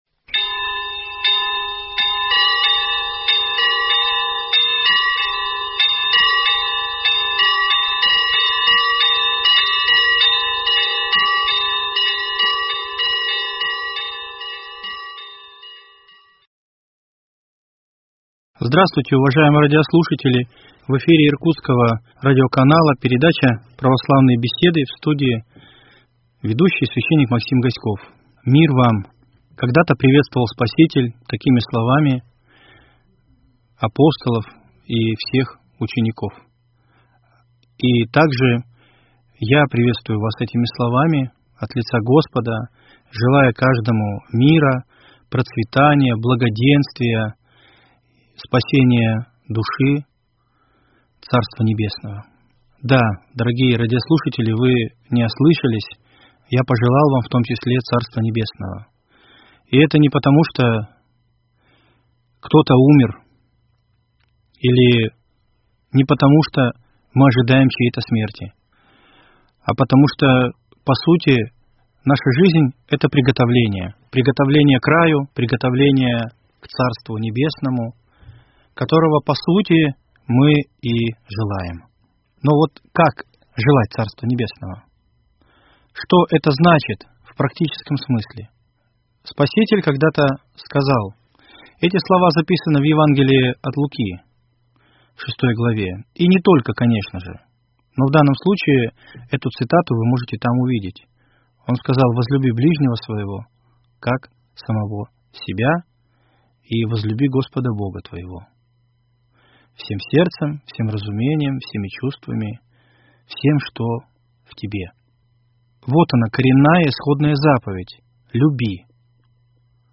Священник